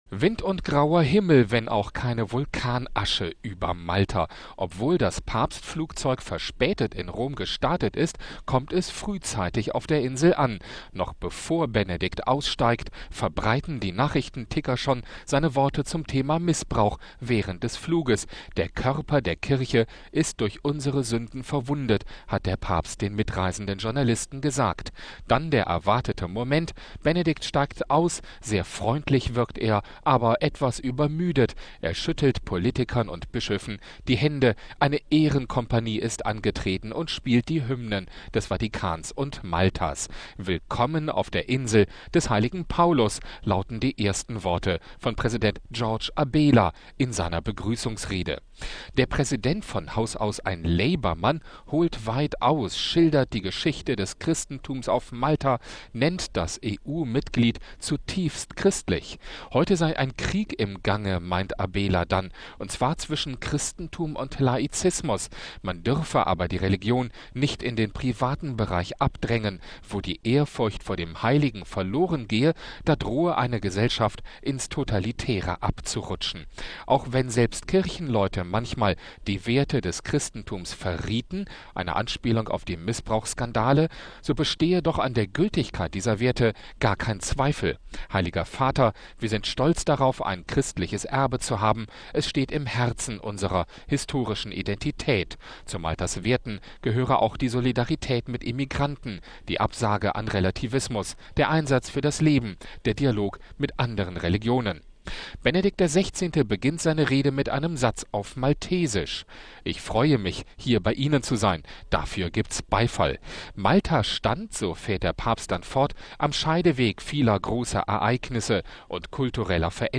Benedikt XVI. beginnt seine Rede mit einem Satz auf Maltesisch: „Ich freue mich, hier bei Ihnen zu sein!“ Dafür gibt`s Beifall.